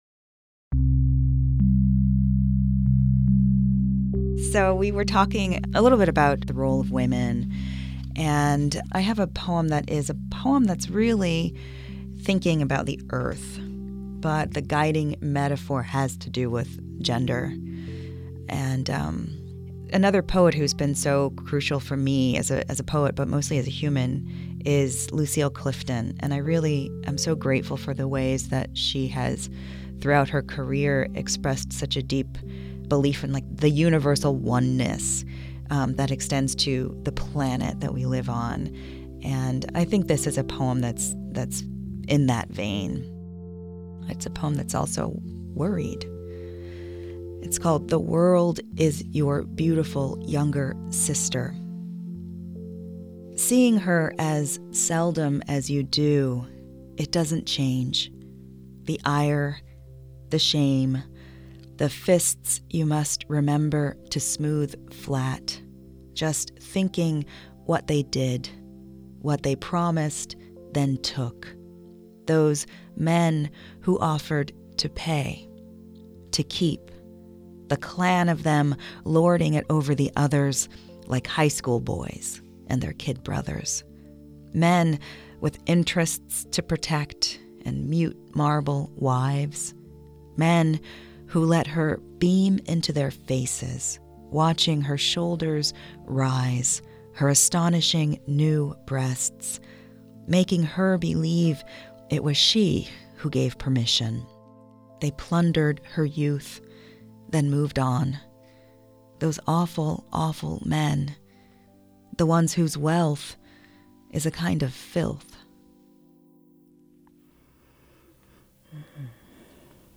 Tracy K. Smith and Rita Dove Reading Their Poetry
In this excerpt, they each read and discuss a new poem. Smith reads "The World is Your Beautiful Younger Sister" from her new book Wade in the Water on Graywolf Press, and Dove reads "Transit" from a yet-to-be-named forthcoming collection.